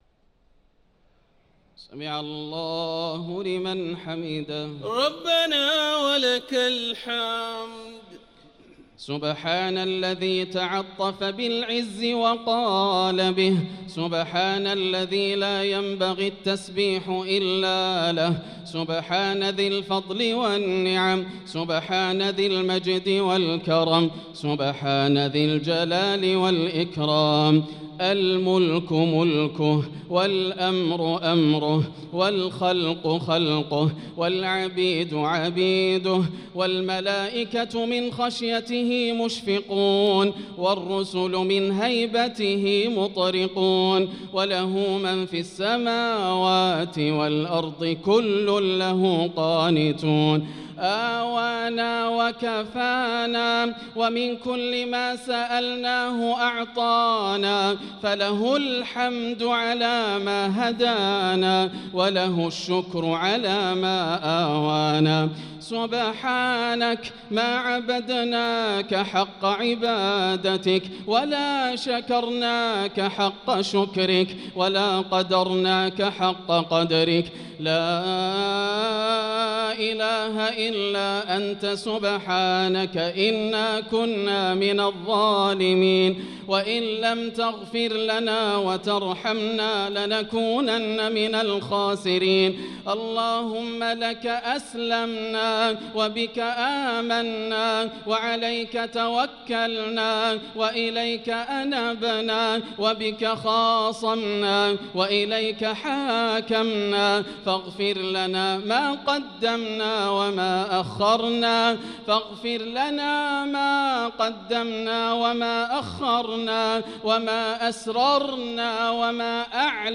صلاة التراويح ليلة 20 رمضان 1445 للقارئ ياسر الدوسري - دعاء القنوت